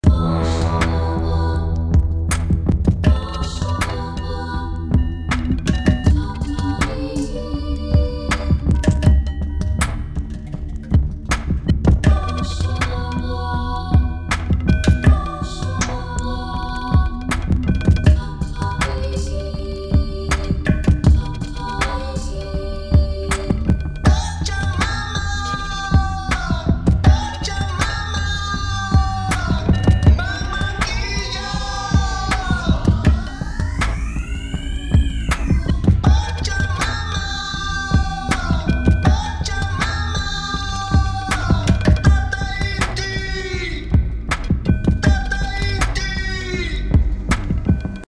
Ich höre mir das gesamte Stück noch einmal an und finde zwei Stellen, die ich noch dringend ändern muss: Zum einen den Woosh-Sound zu Beginn, der nach einem schrillen Eisenbahngeräusch klingt, zum anderen die Abfolge des „Ladies Solo“ und des „Refrains“. Hier schreit der Sänger beinahe, hebt sich jedoch nicht ausreichend dominant zu den vorangehenden weiblichen Vocals ab:
Außerdem klingt der Einsatz der männlichen Stimme unangenehm phasig.